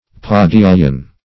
Search Result for " padelion" : The Collaborative International Dictionary of English v.0.48: Padelion \Pad`e*li"on\, n. [F. pas de lion lion's foot.]